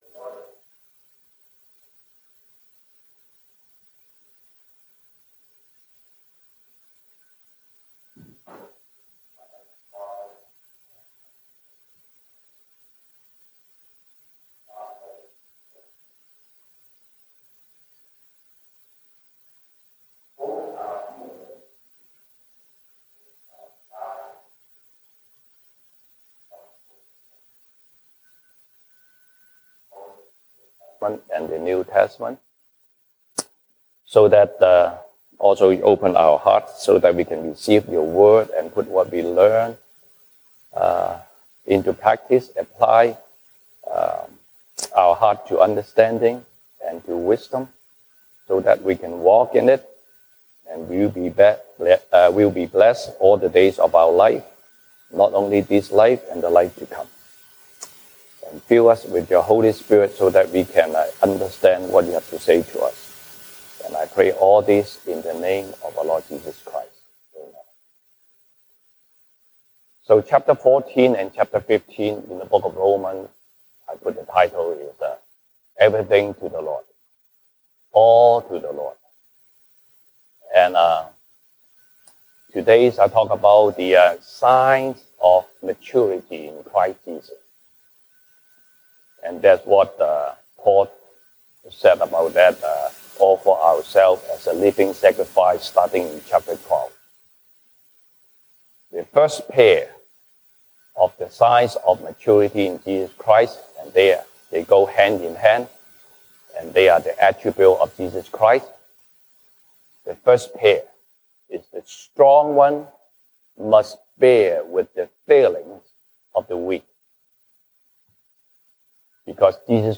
西堂證道 (英語) Sunday Service English: Everything to the Lord